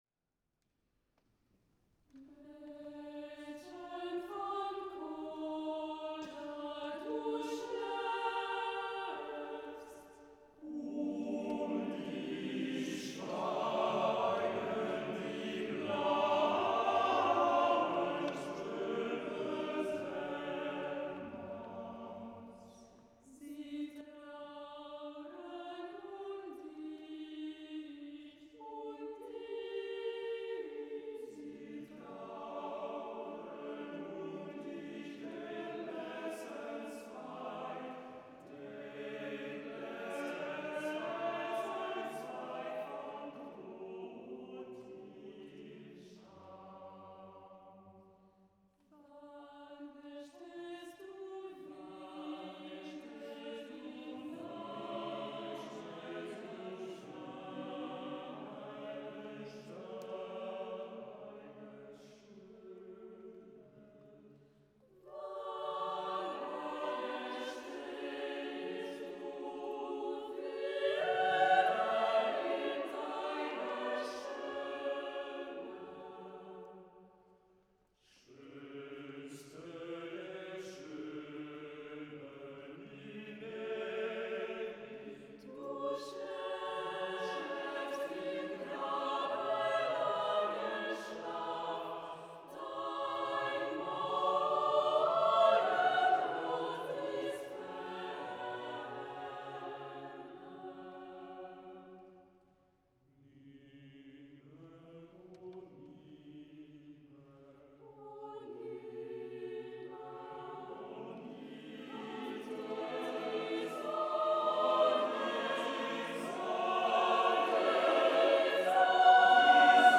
Retrouvez ici des extraits « live » de nos concerts !